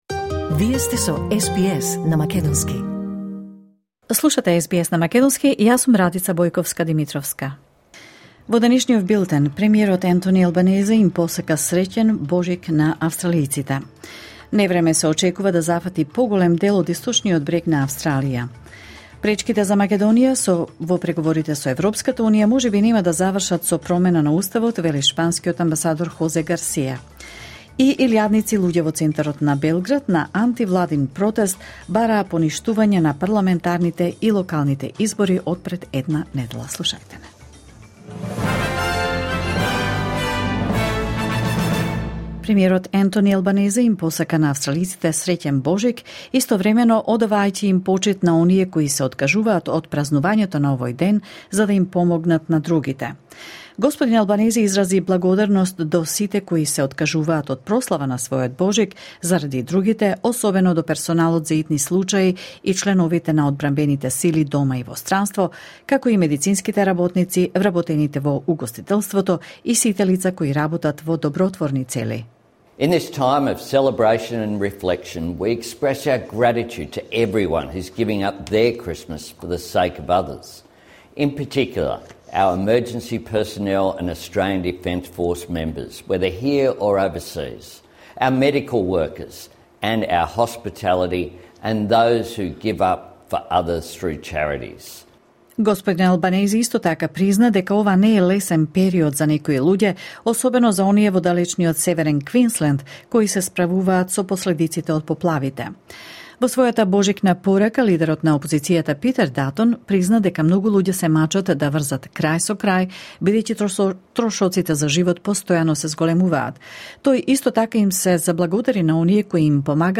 SBS News in Macedonian 25 December 2023